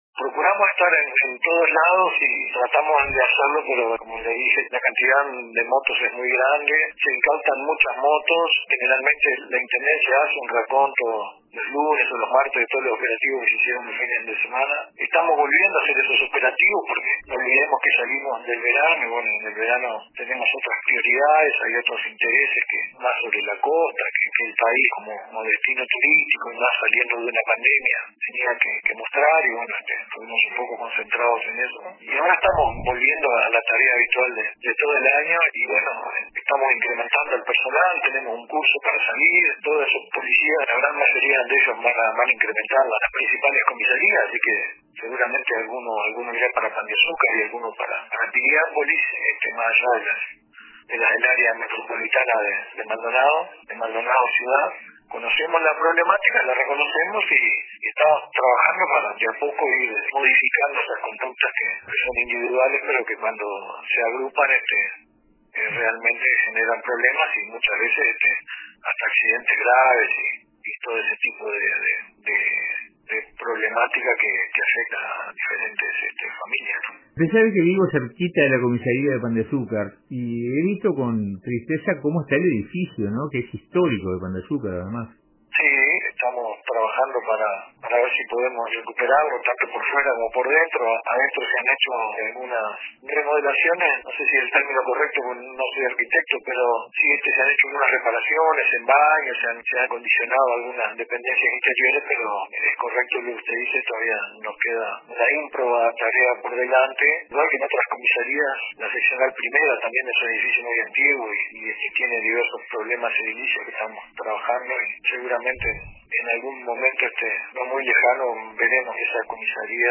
El Jefe de Policía de Maldonado, Julio Pioli habló con RADIO RBC sobre los operativos combinados que desde la semana pasada se realizan en el departamento.